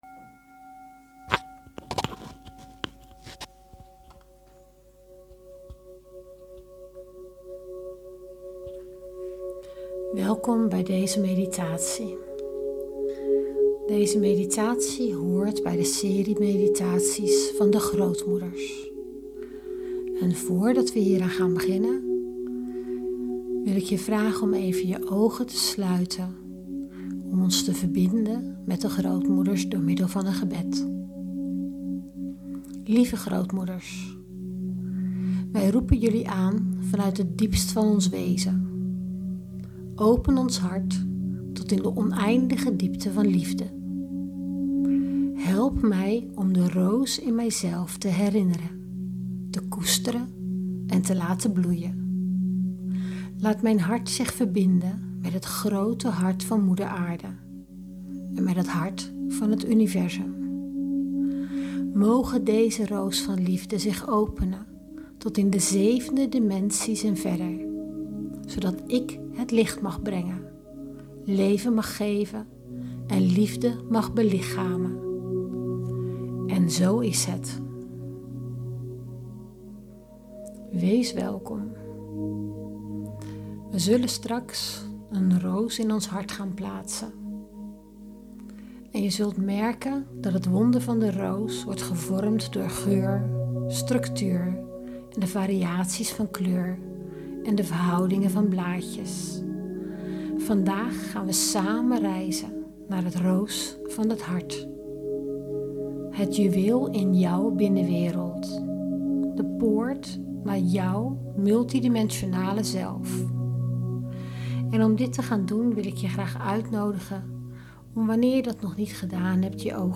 Geleide Meditatie – De Roos van het Hart | Open je Hart - Spiritueel Centrum Jolie
Deze bijzondere geleide meditatie wordt vaak het juweel van de oefeningen genoemd, omdat zij je helpt je hart écht te openen. Tijdens deze meditatie word je stap voor stap begeleid naar meer liefde, rust en verbinding met jezelf.